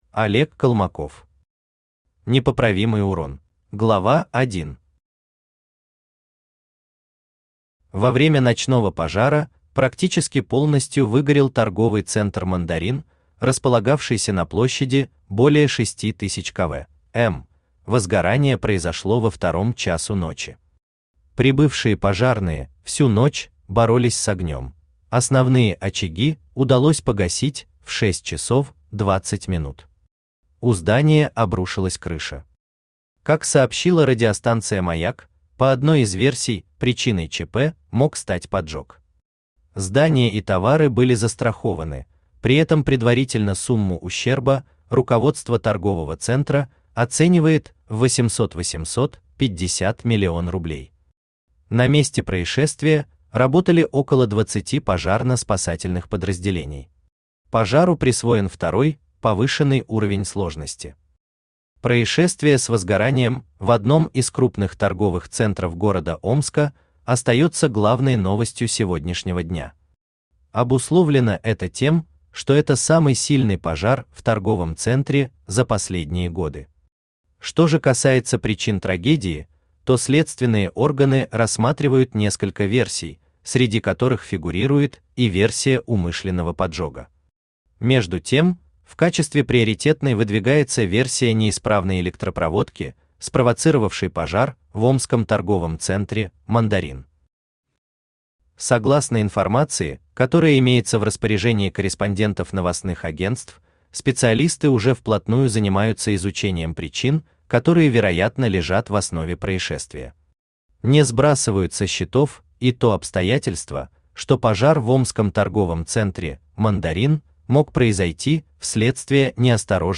Аудиокнига Непоправимый урон | Библиотека аудиокниг
Aудиокнига Непоправимый урон Автор Олег Колмаков Читает аудиокнигу Авточтец ЛитРес.